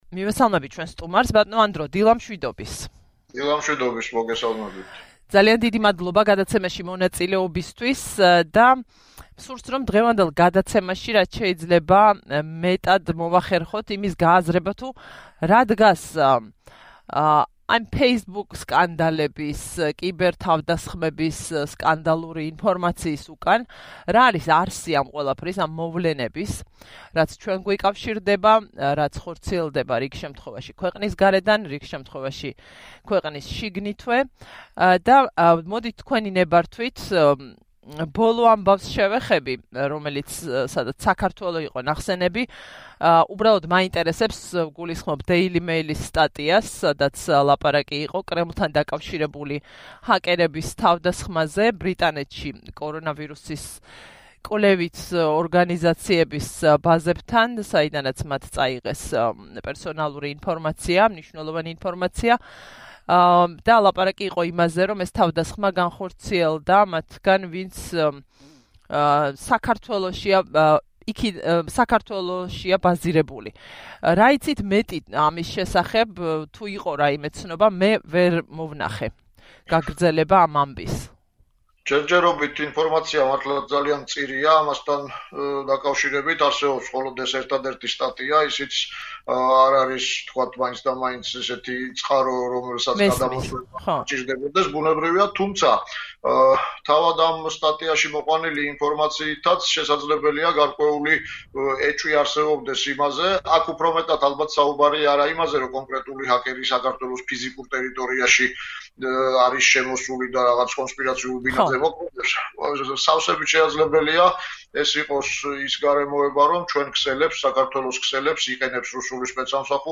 კიბერტექნოლოგიების სპეციალისტი რადიო თავისუფლების ეთერში